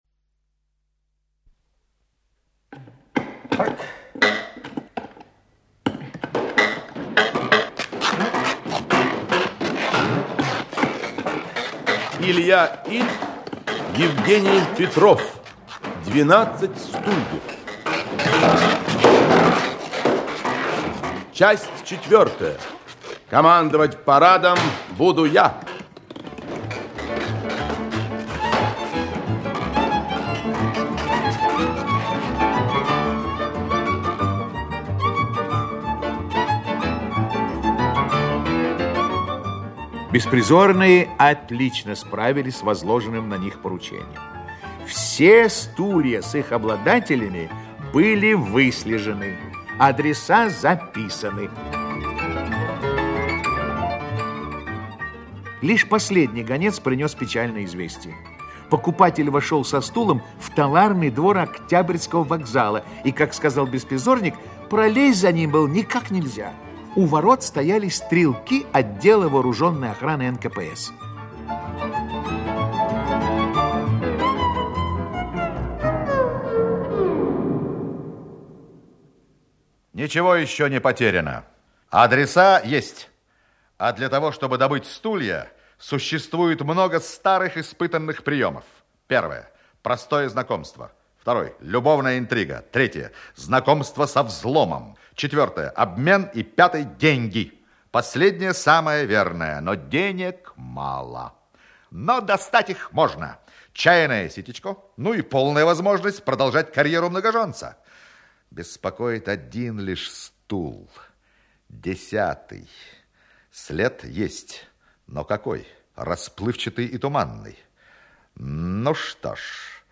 Аудиокнига 12 стульев (спектакль) Часть 4-я. Командовать парадом буду я!
Автор Илья Ильф Читает аудиокнигу Актерский коллектив.